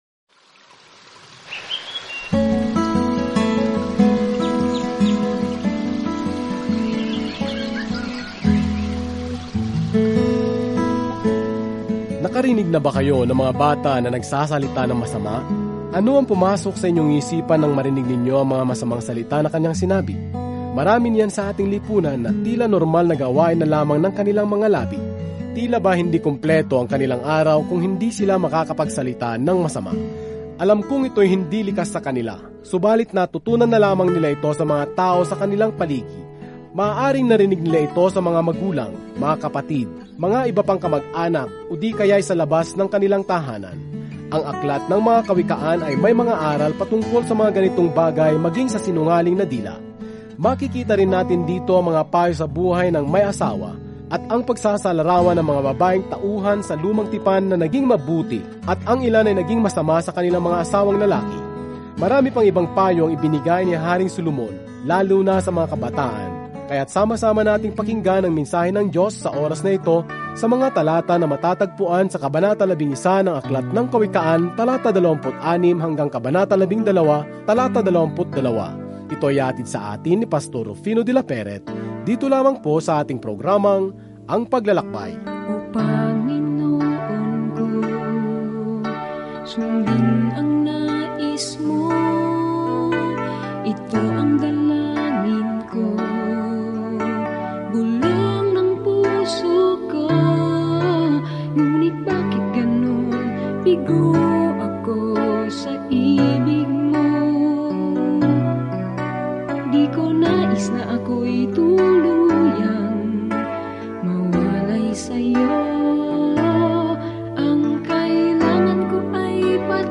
Araw-araw na paglalakbay sa Mga Kawikaan habang nakikinig ka sa audio study at nagbabasa ng mga piling talata mula sa salita ng Diyos.